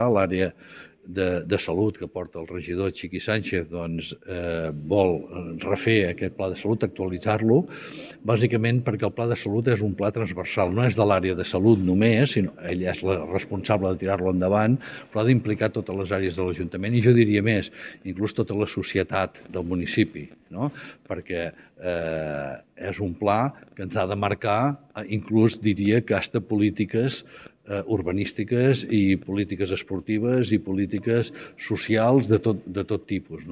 Durant aquestes trobades, es compartiran els resultats de la diagnosi prèvia i, al mateix temps, es recolliran aportacions que serviran per concretar els objectius i les accions del futur document. Ho explica l’alcalde de Torroella de Montgrí i l’Estartit, Jordi Colomí.